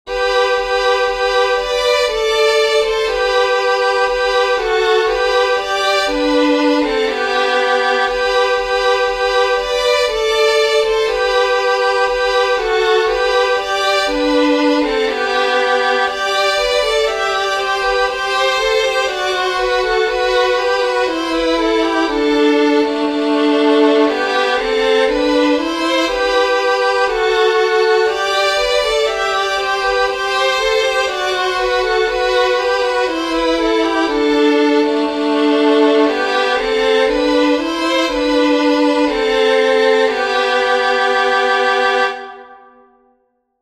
Four more Christmas tunes to try at home. If you can find another fiddler to do the harmony, it is even more fun.